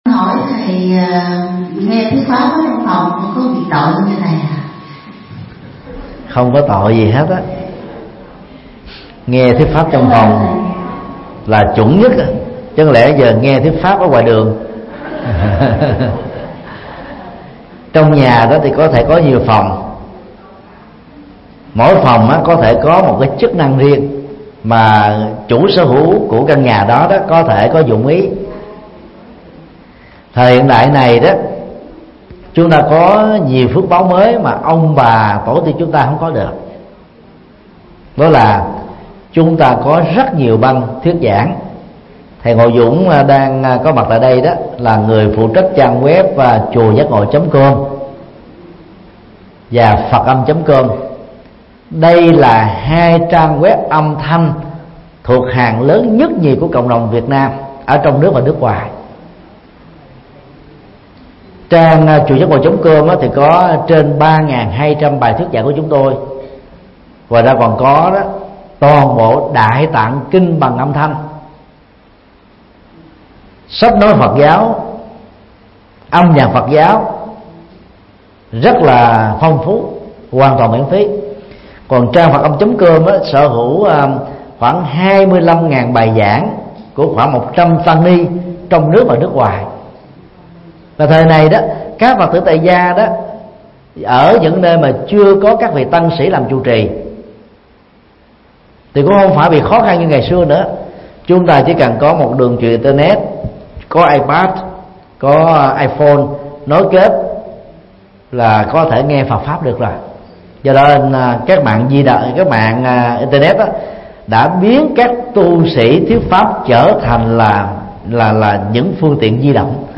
Vấn đáp: Nghe pháp thời hiện đại – Thích Nhật Từ